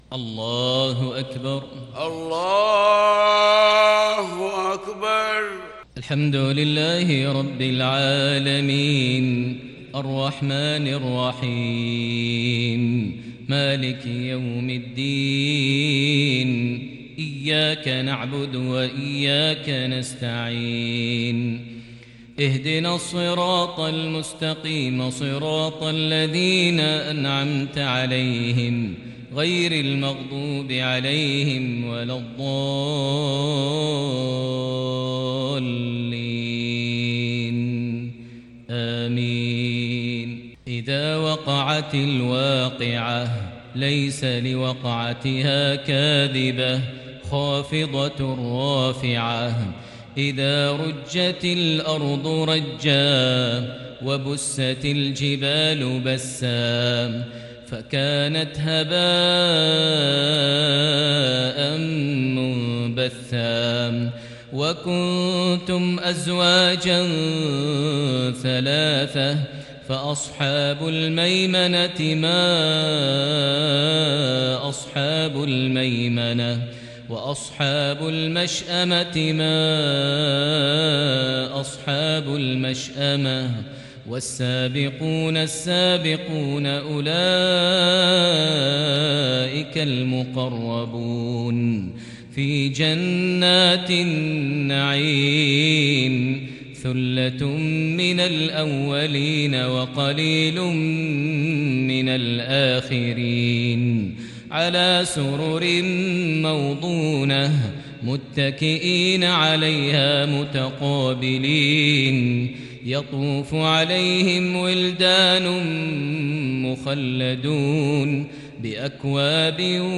صلاة العشاء للشيخ ماهر المعيقلي 23 صفر 1442 هـ
تِلَاوَات الْحَرَمَيْن .